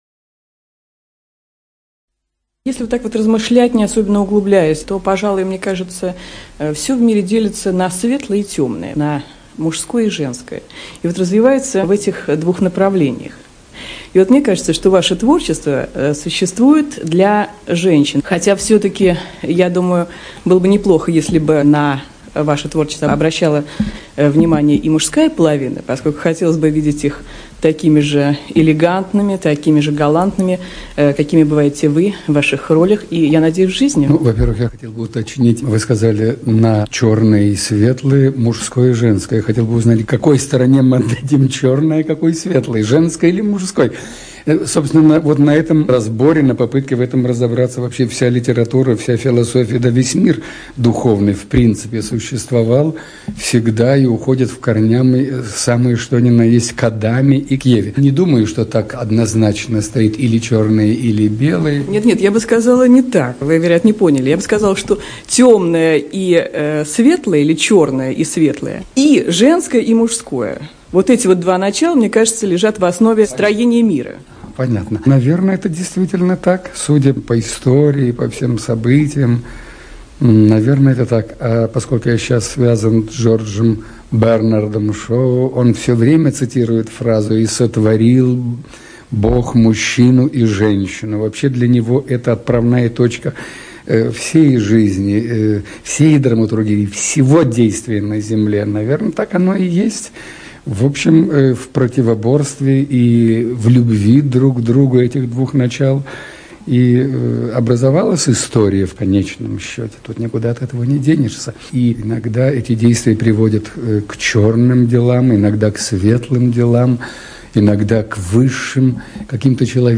НазваниеИнтервью. 1994
Лановой В - Интервью. 1994(preview).mp3